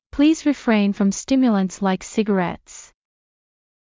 ﾌﾟﾘｰｽﾞ ﾘﾌﾚｲﾝ ﾌﾛﾑ ｽﾃｨﾐｭﾗﾝﾂ ﾗｲｸ ｼｶﾞﾚｯﾂ